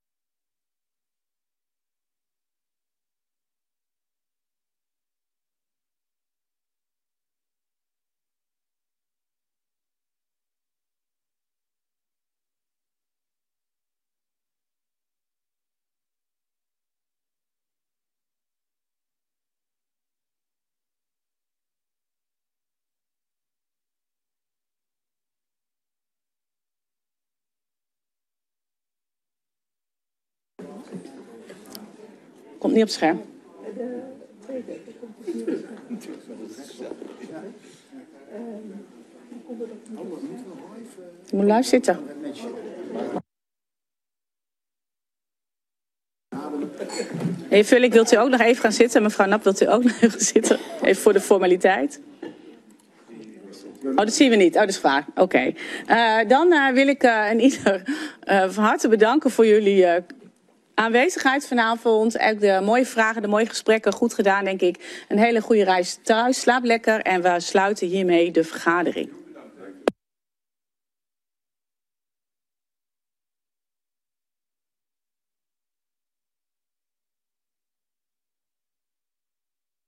Oordeelsvormende vergadering 09 oktober 2025 19:30:00, Gemeente Dronten
Download de volledige audio van deze vergadering